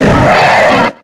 Cri de Drackhaus dans Pokémon X et Y.